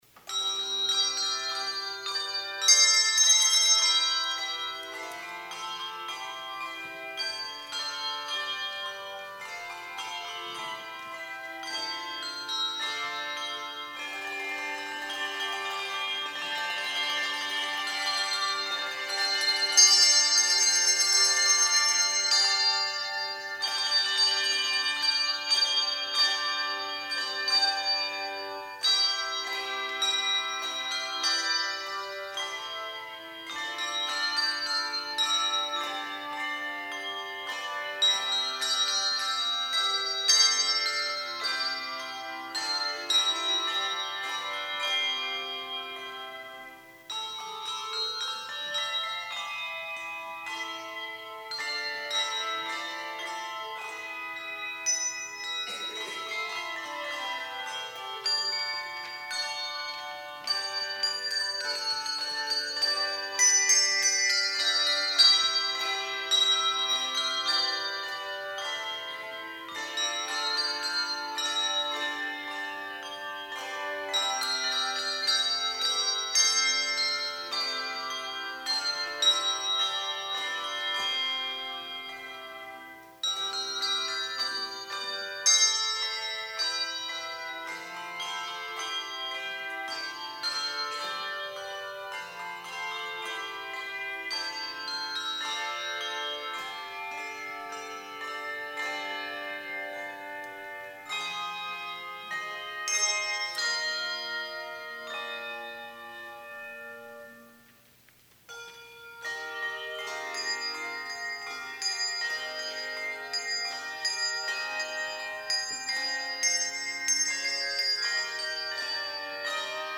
HANDBELL CHOIR Thine Is the Glory arr. Cynthia Dobrinski, b. 1950